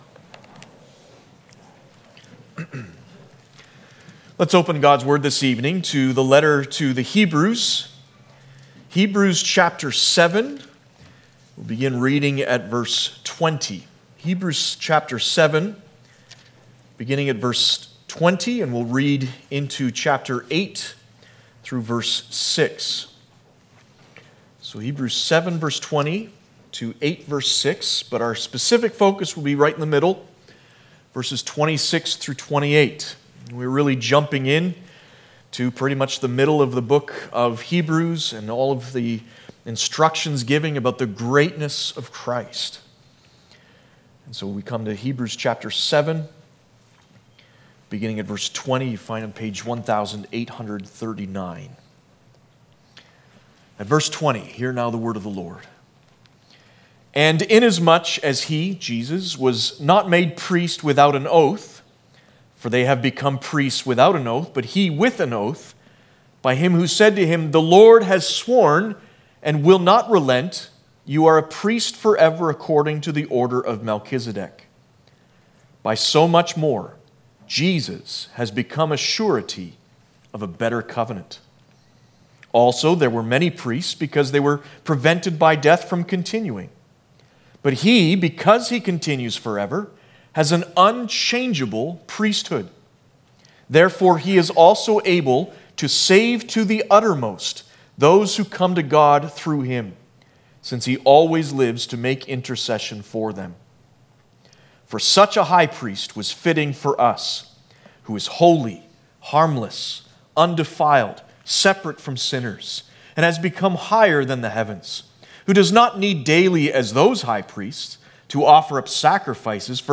Passage: Hebrews 7:26-28 Service Type: Ascension Day